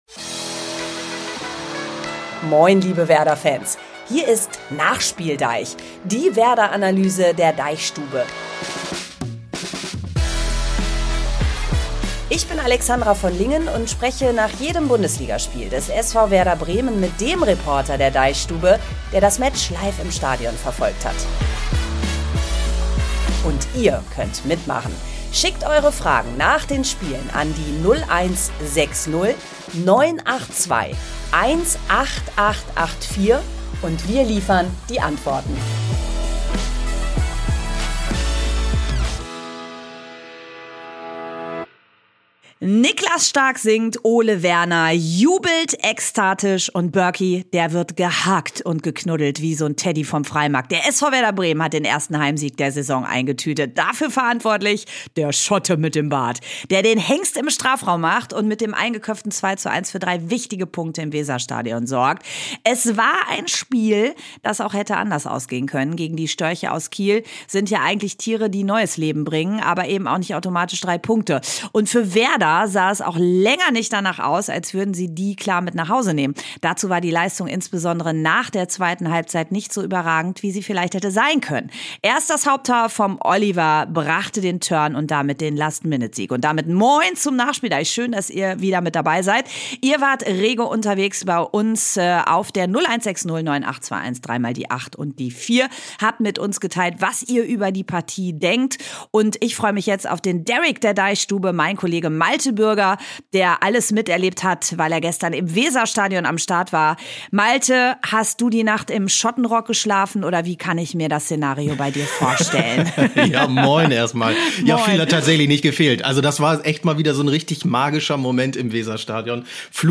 NachspielDEICH ist ein Fußball-Podcast der DeichStube.